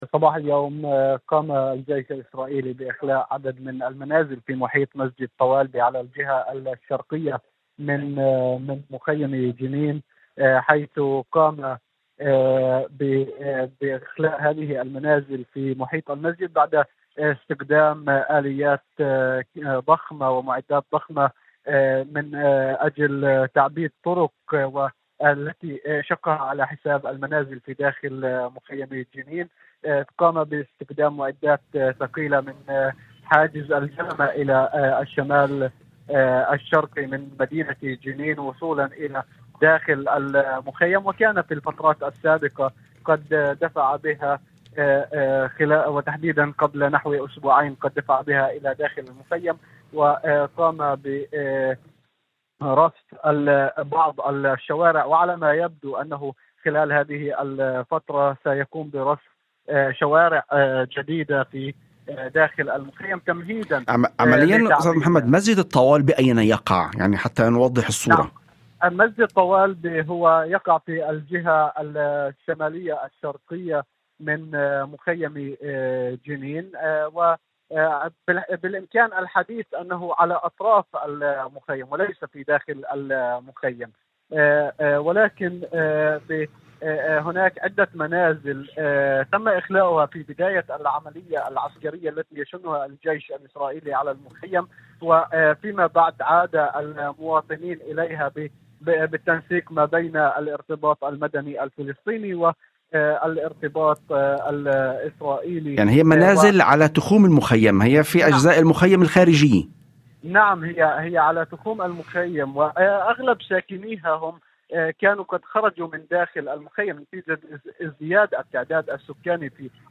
وأضاف في مداخلة هاتفية لبرنامج "يوم جديد"، على إذاعة الشمس، أن مسجد الطوالبة يقع على تخوم مخيم جنين، في الجهة الشمالية الشرقية منه، وليس في عمق المخيم، موضحًا أن المنازل التي جرى إخلاؤها تقع في المناطق الخارجية الملاصقة للمخيم.